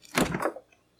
Refrigerator open #2 (sound effects)
A sound effect that opens the refrigerator....